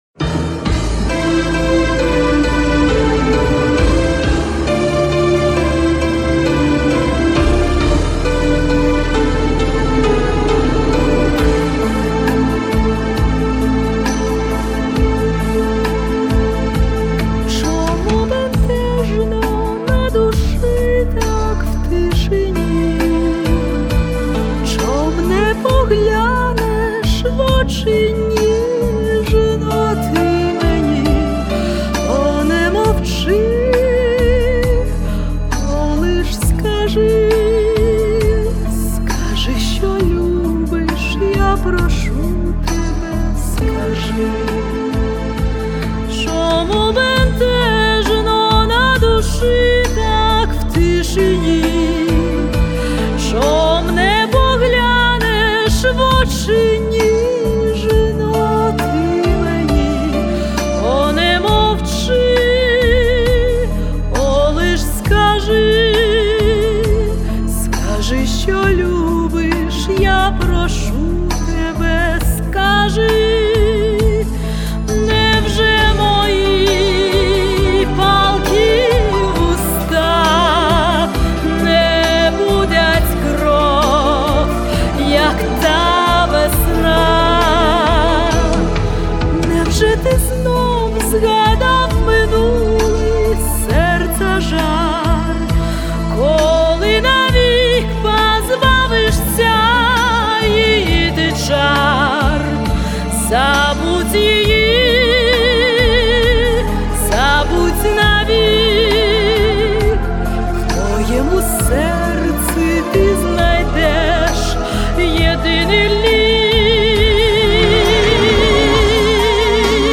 ЖИВОЙ ЗВУК